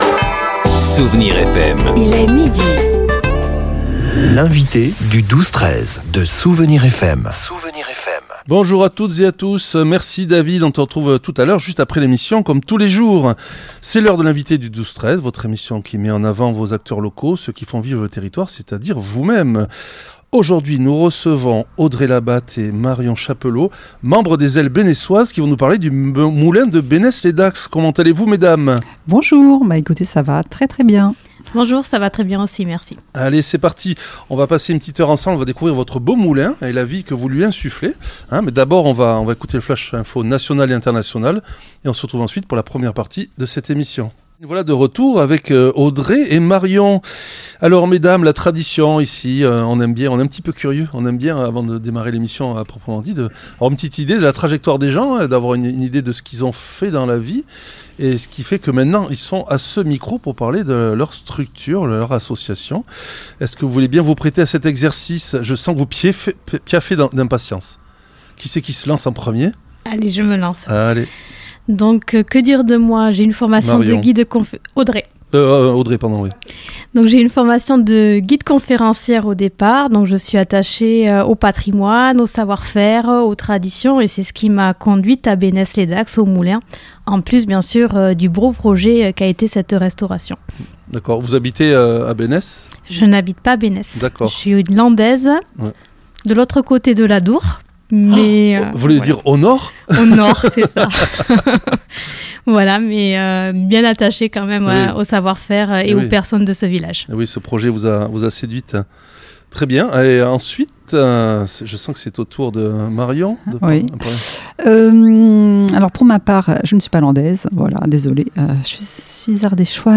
L'entretien a permis de dévoiler une programmation 2026 riche en nouveautés, avec l'ouverture d'une salle d'immersion, des jeux d'antan et une future restauration estivale.